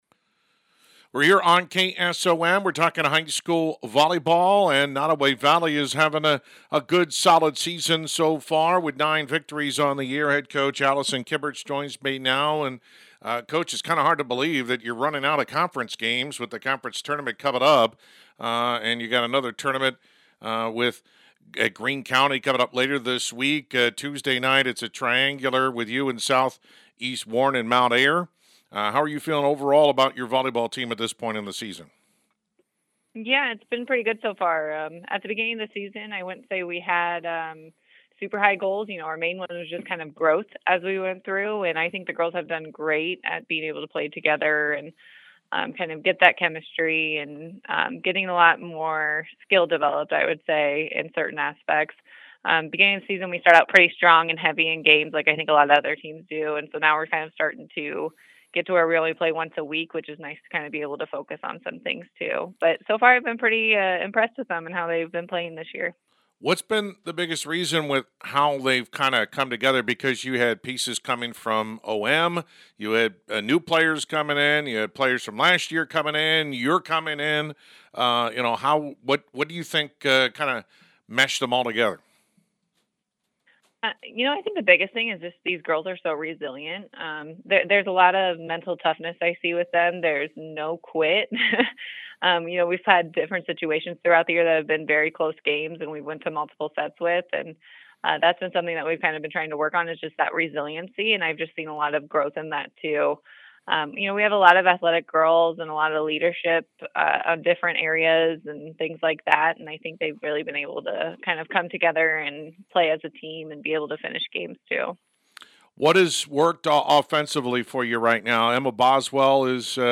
Complete Interview
nodaway-valley-volleyball-9-30.mp3